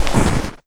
STEPS Snow, Run 23.wav